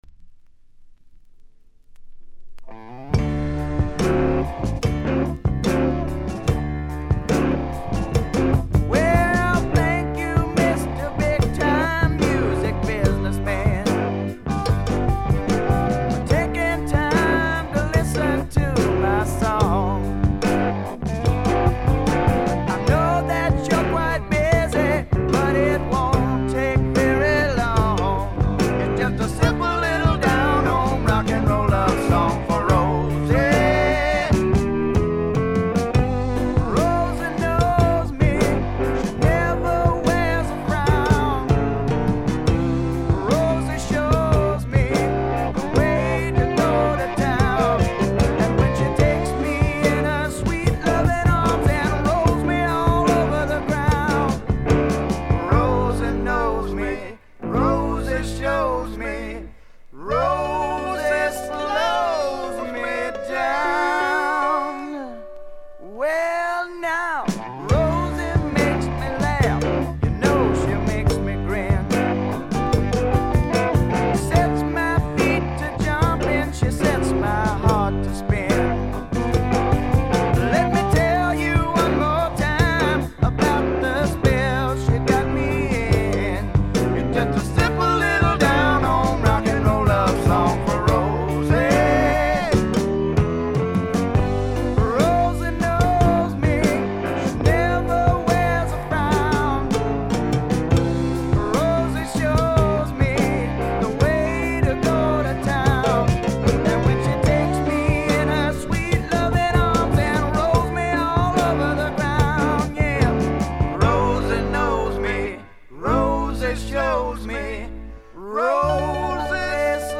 軽いチリプチが少々。
試聴曲は現品からの取り込み音源です。